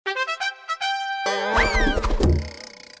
14-sfx-arrow-post-mp3cut_1.mp3